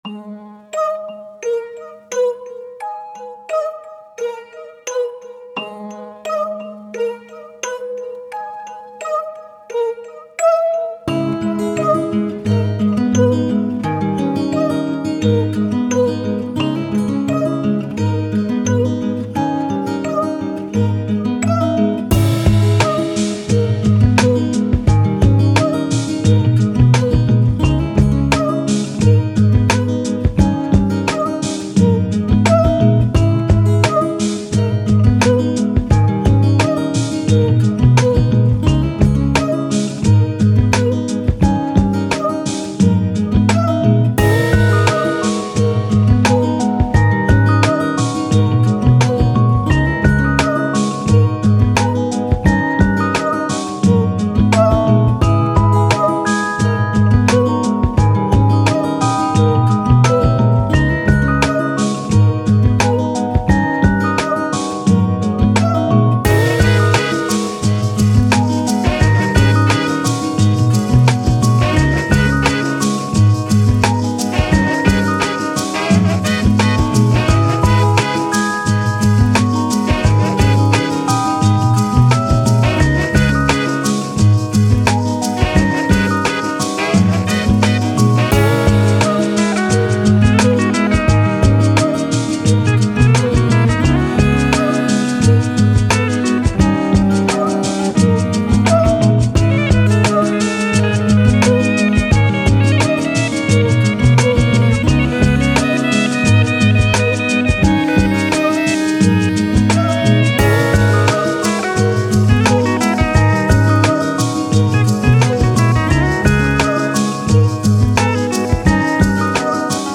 Downtempo, Reverse, Quirky, Landscapes, Thoughtful